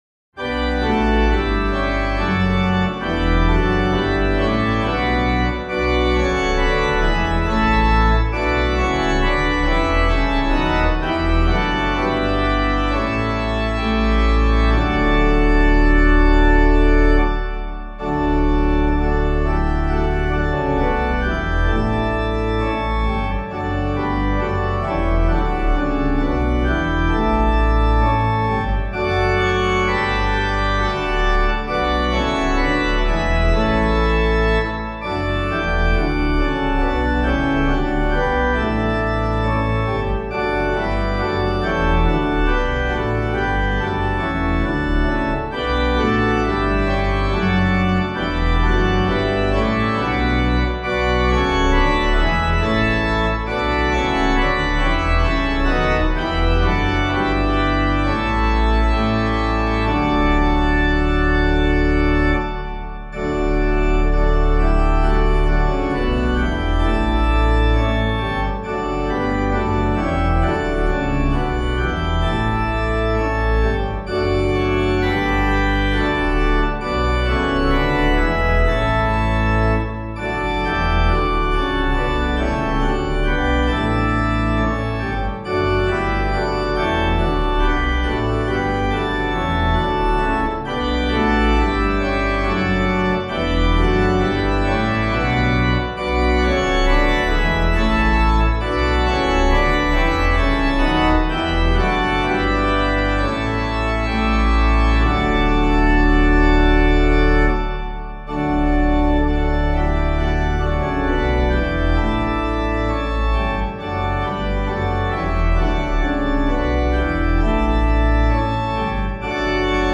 organpiano